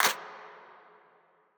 TS - CLAP (10).wav